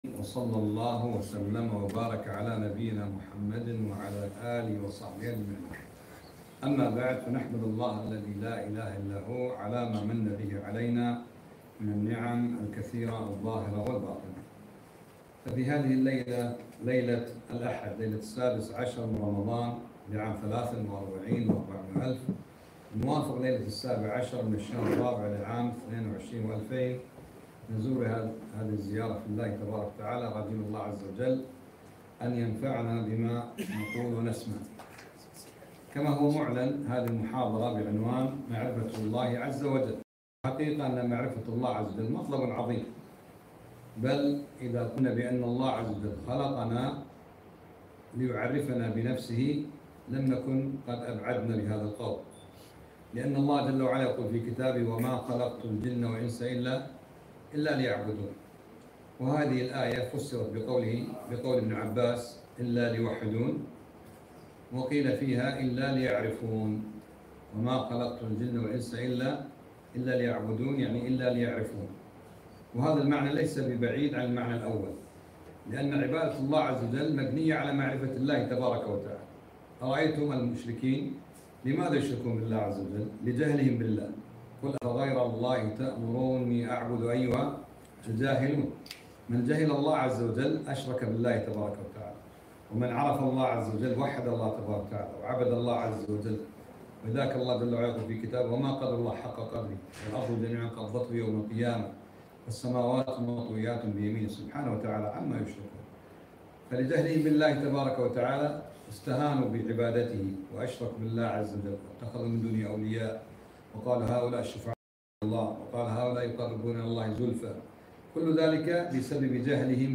كلمة - معرفة الله